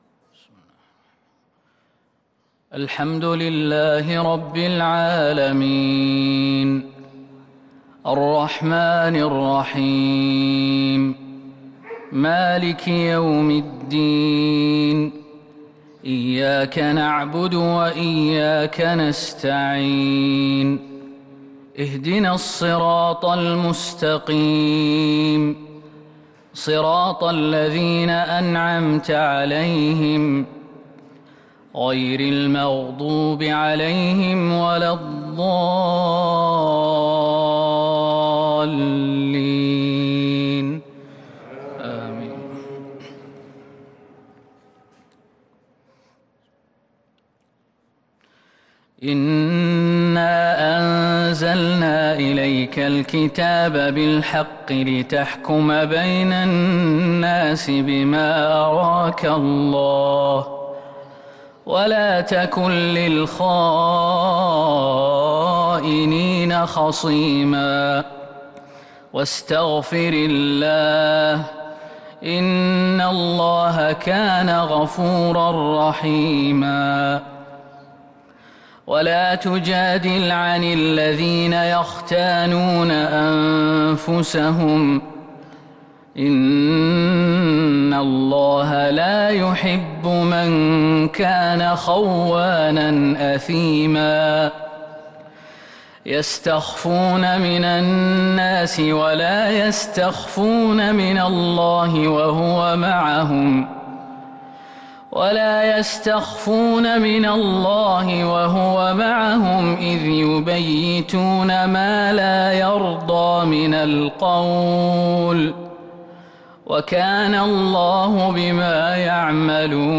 مغرب الأربعاء 1 صفر 1443هـ | من سورة النساء | Maghrib prayer from Surah An-Nisaa 8/9/2021 > 1443 🕌 > الفروض - تلاوات الحرمين